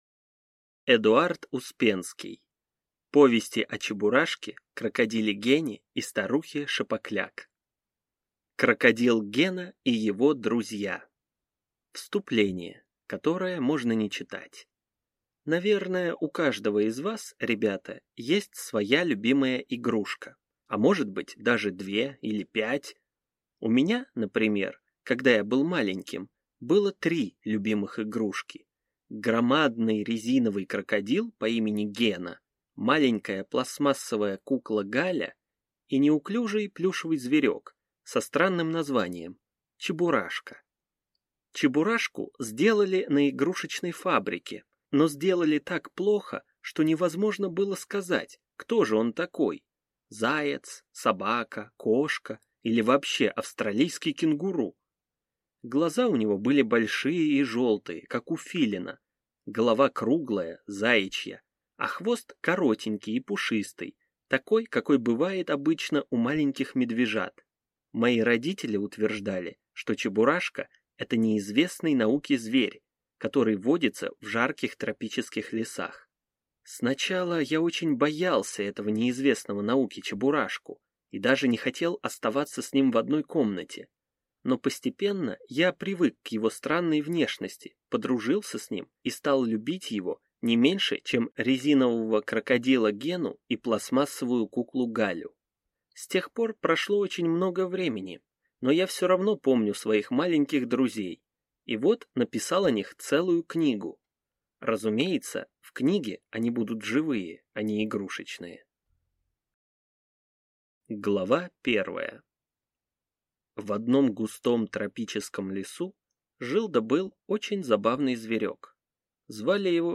Аудиокнига Повести о Чебурашке, крокодиле Гене и старухе Шапокляк | Библиотека аудиокниг